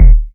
KICK.120.NEPT.wav